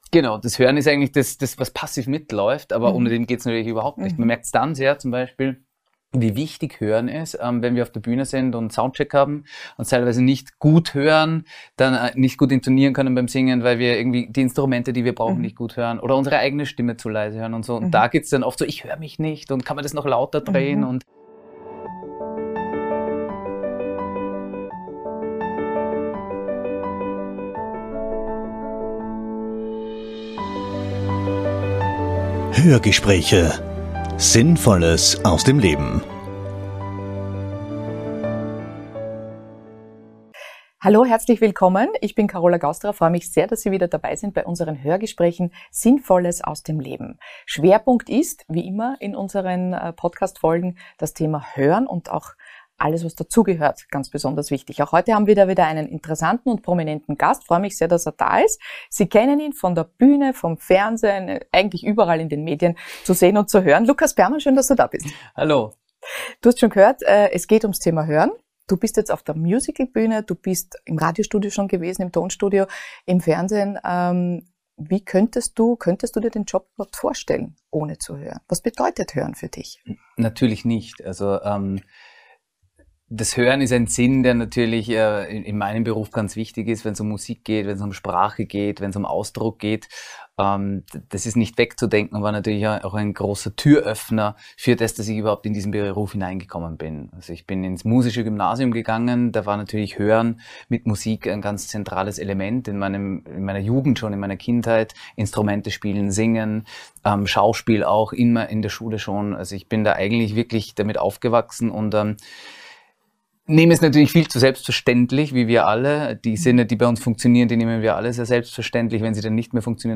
Lukas Perman weiß, wie wichtig seine gute Beziehung zu seiner Stimme ist, wie besonders ein gutes Hörvermögen und der Ausgleich, den er in der Ruhe sucht. Erfahren Sie im sympathischen Interview mehr über den charismatischen Sänger, Schauspieler und Musiker, der sehr bewusst mit seinen Sinnen umgeht.